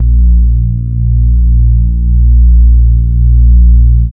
Swaying Bass 65-02.wav